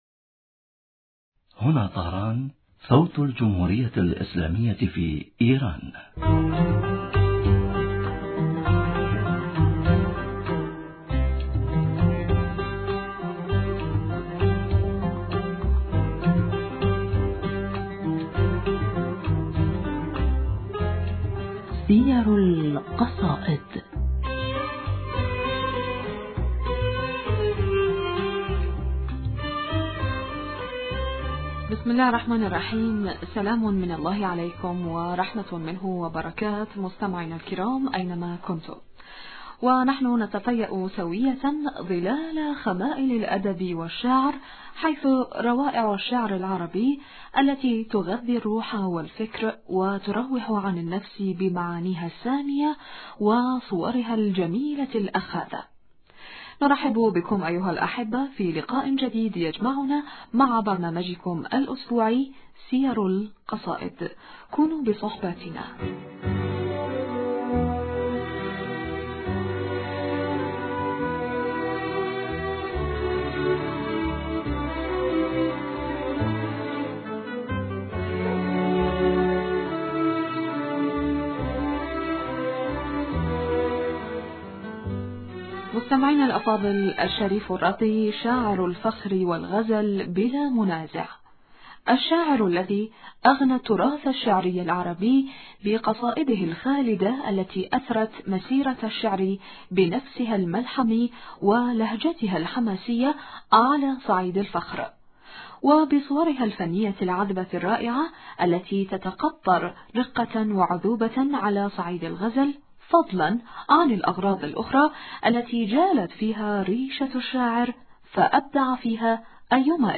المحاورة: